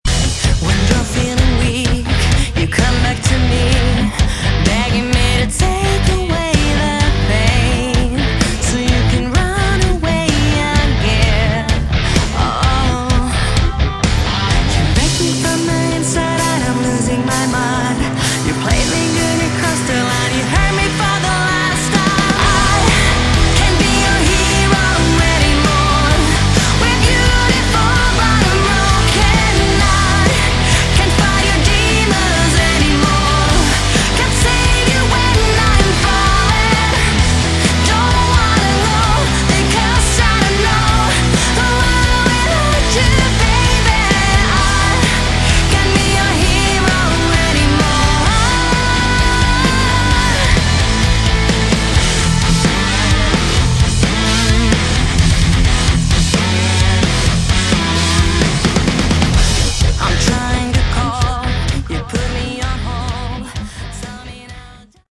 Category: Melodic Rock
vocals
guitar
bass
drums